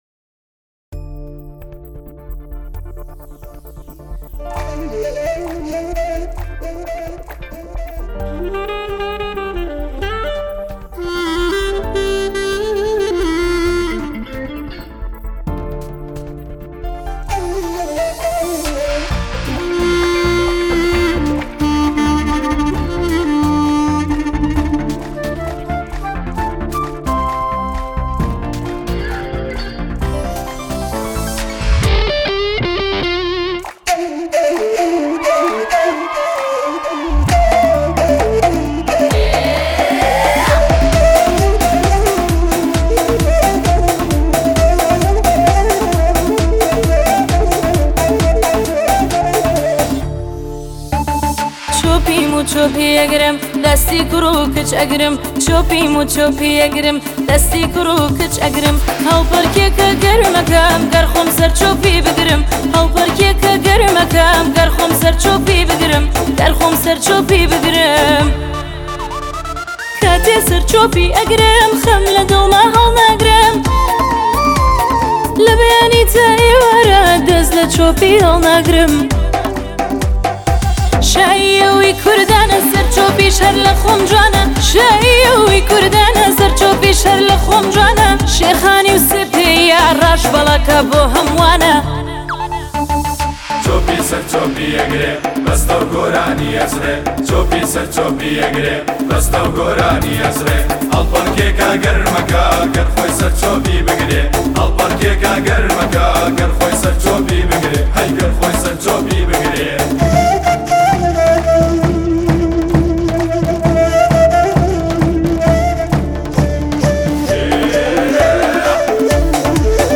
آهنگ کردی فولکلور
هورامی